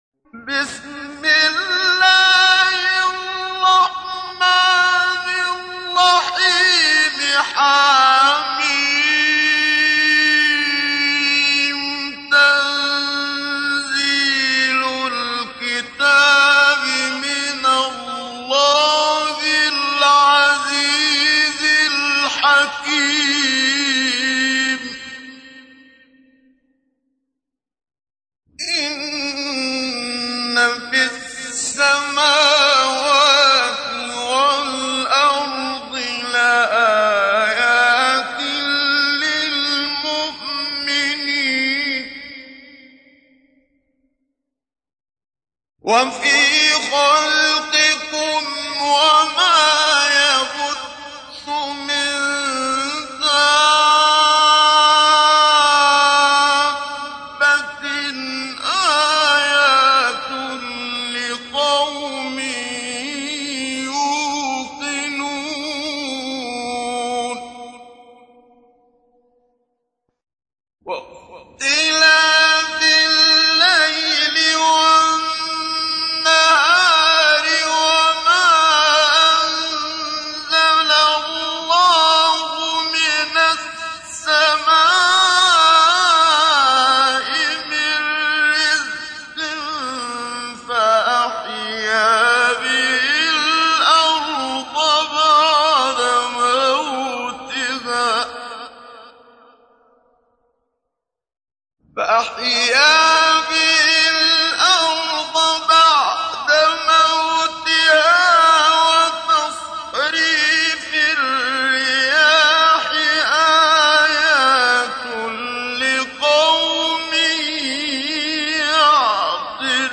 تحميل : 45. سورة الجاثية / القارئ محمد صديق المنشاوي / القرآن الكريم / موقع يا حسين